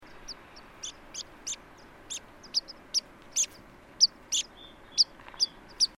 Vuorikirvinen / Water Pipit (Anthus spinoletta)
(10.)Heinäkuu 2005 Kirgisia Lentoääniä ja varoitusääniä sekaisin. / July 2005, Kyrgyzstan. Calls.